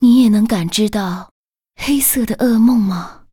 文件 文件历史 文件用途 全域文件用途 Dana_amb_03.ogg （Ogg Vorbis声音文件，长度3.3秒，95 kbps，文件大小：39 KB） 源地址:游戏语音 文件历史 点击某个日期/时间查看对应时刻的文件。